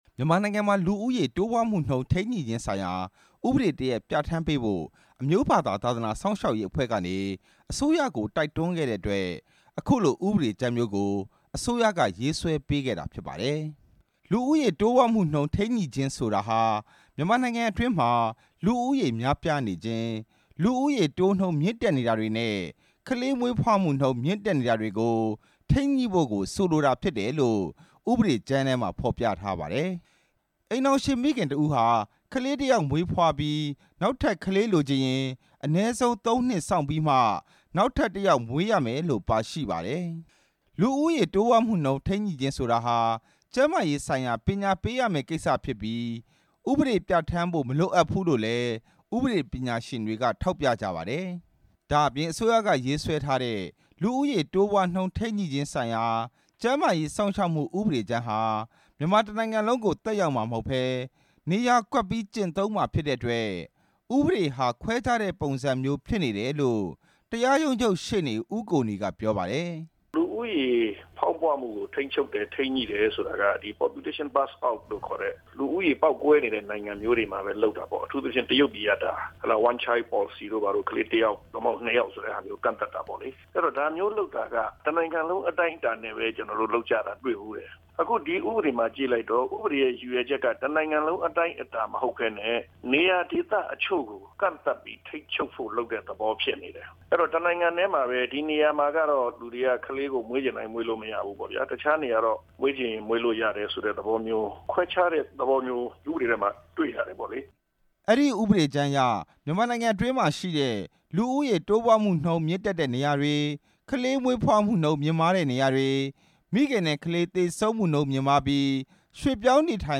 ဗဟိုအလုပ်အမှုဆောင် ဦးဝင်းထိန်ကို မနေ့က တွေ့ဆုံမေးမြန်းထားပါတယ်။